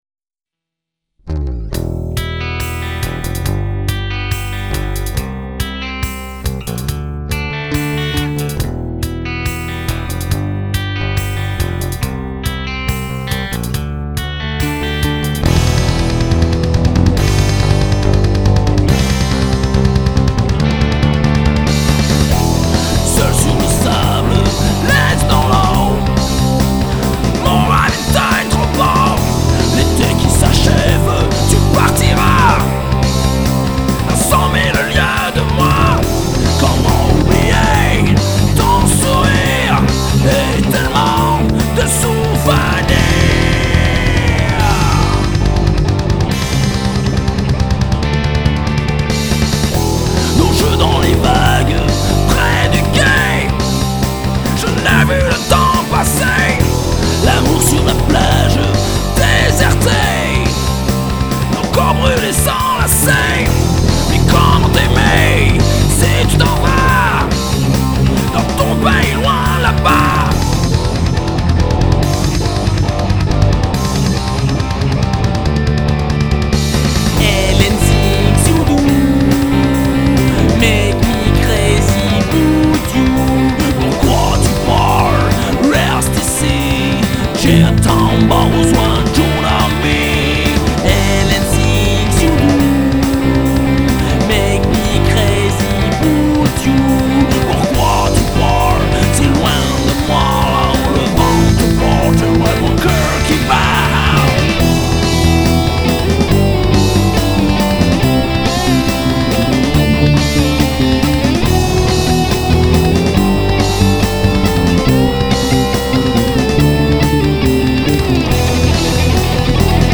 version métal-néopnk-décadent-à-tendance-suicidaire